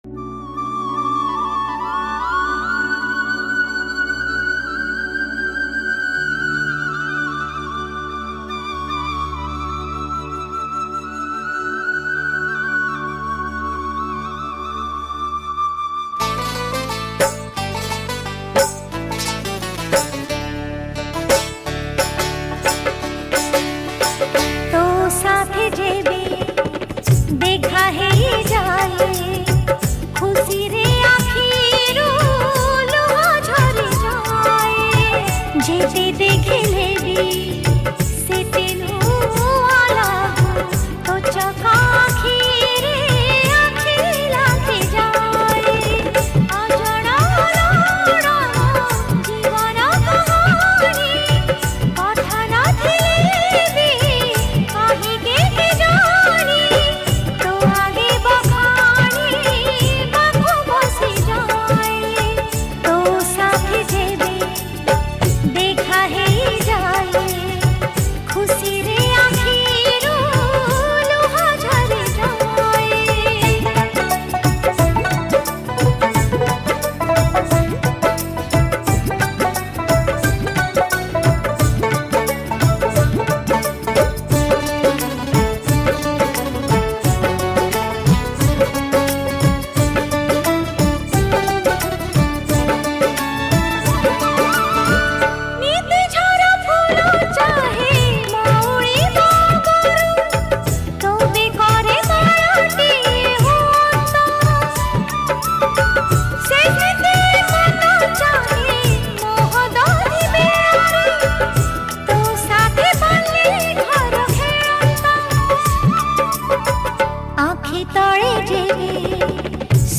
Odia Bhakti Song